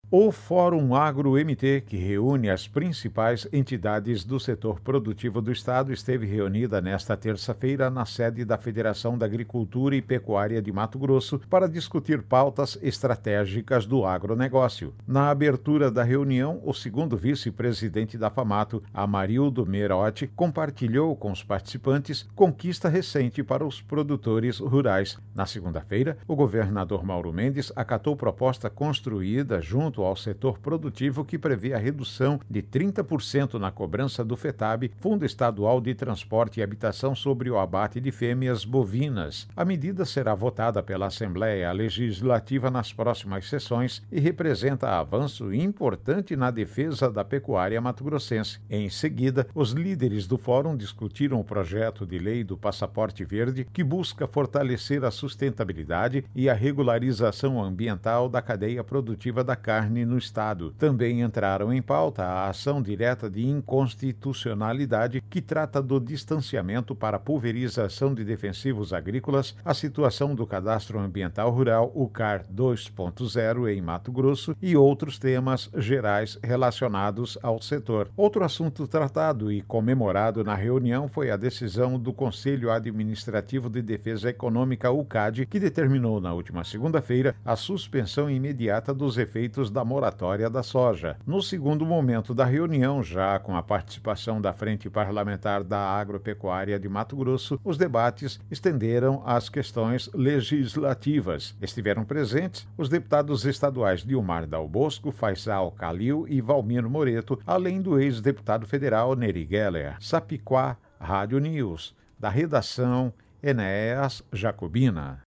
Sapicuá Comunicação – Assessoria e Produções em Áudio Radioagência Sápicuá de Notícias